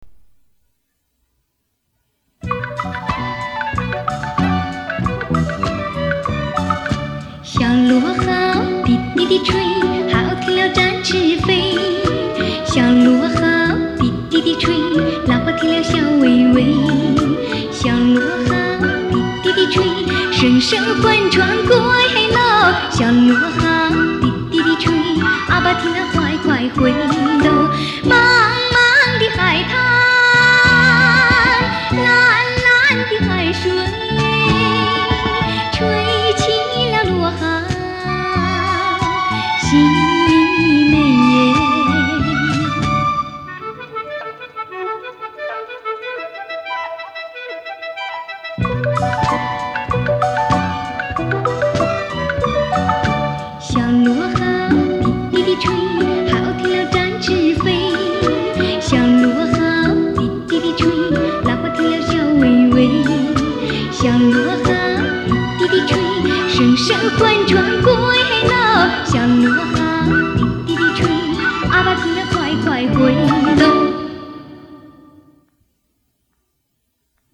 以其清新纯美的演唱风格奠定了她在当时中国歌坛的领先地位